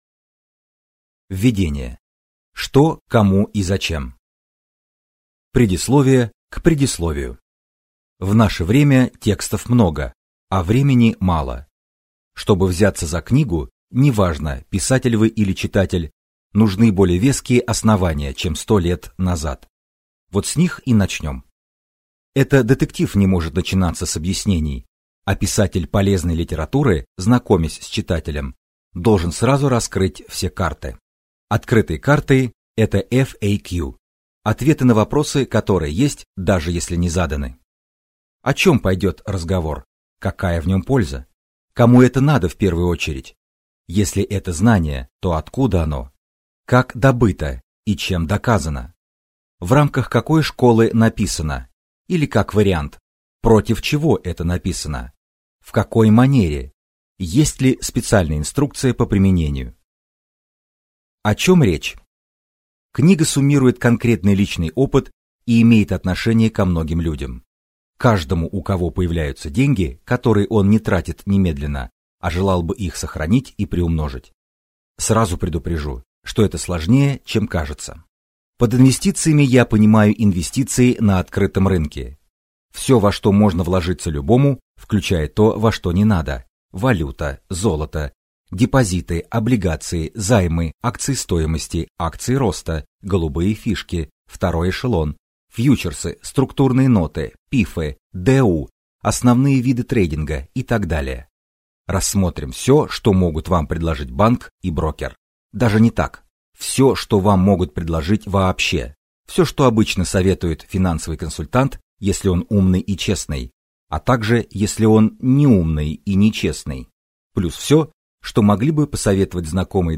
Аудиокнига Деньги без дураков | Библиотека аудиокниг